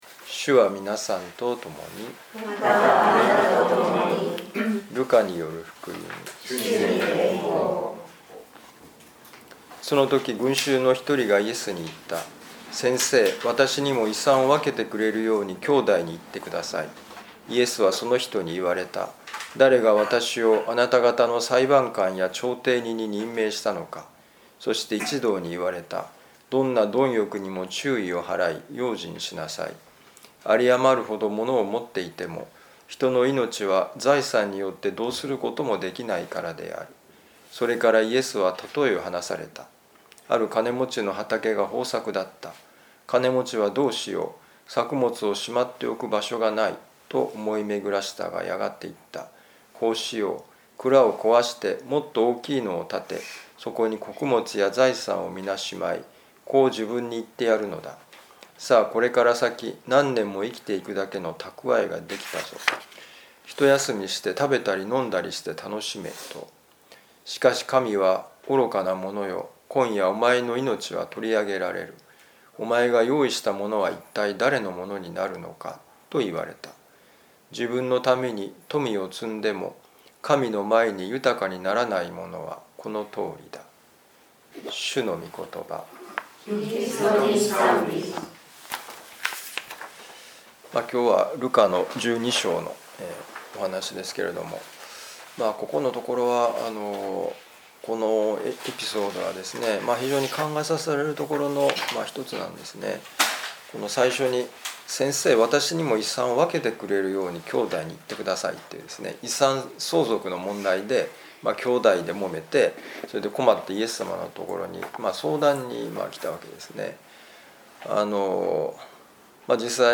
ルカ福音書12章13-21節「外側の問題」2025年10月20日いやしのミサ旅路の里